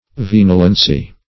Vinolency \Vin"o*len*cy\, n.